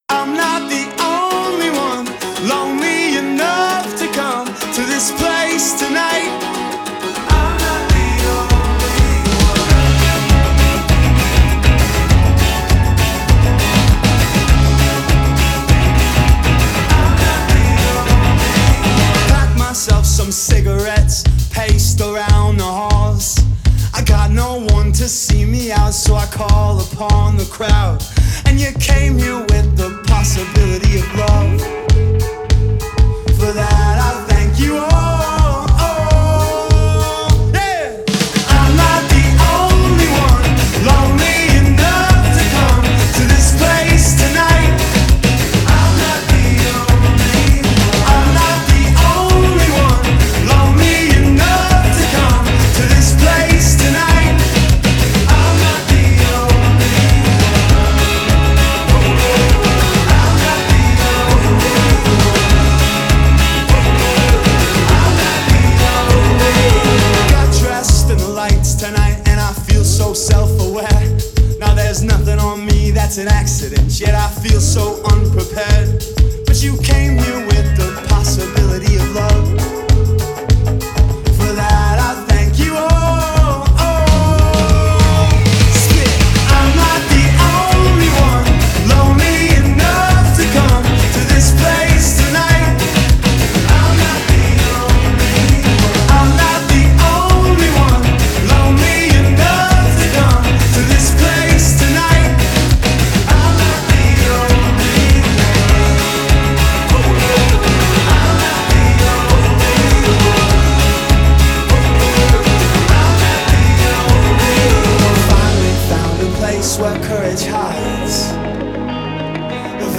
plus 28 min of Western Canadian indie music mix